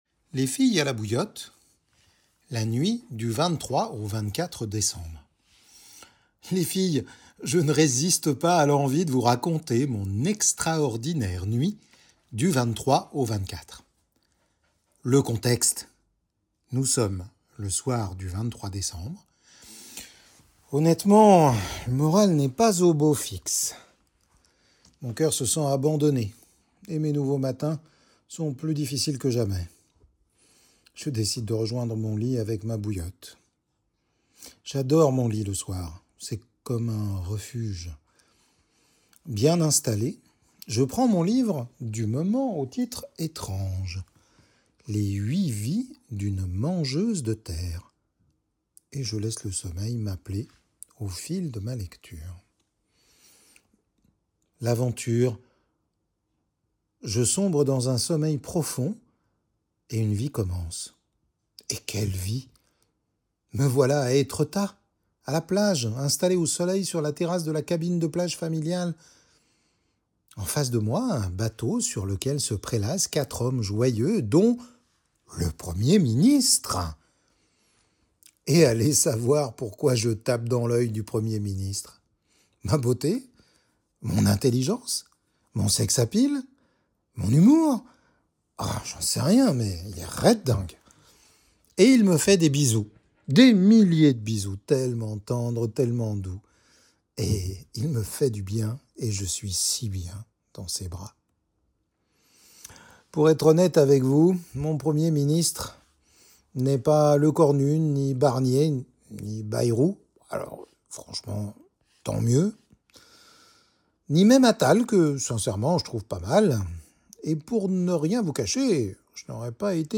28 Jan 2026 | Article audio